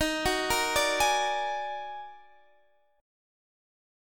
Listen to EbmM11 strummed